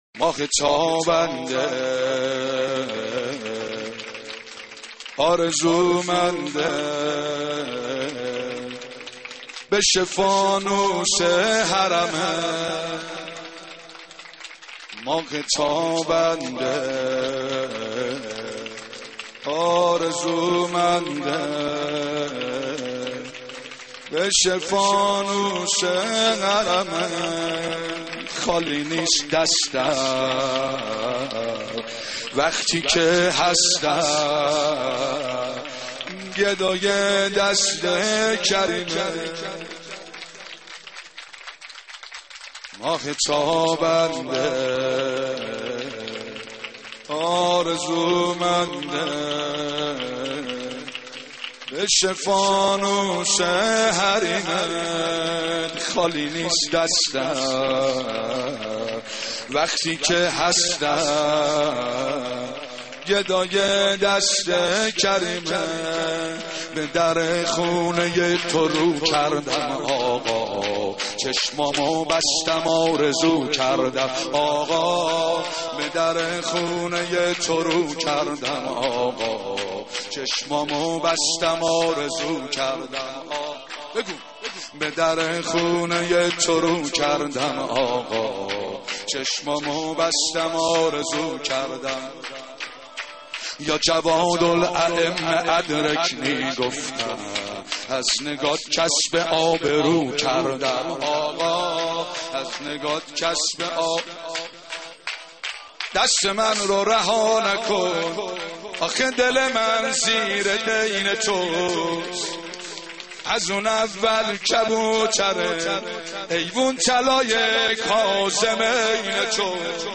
دست زنی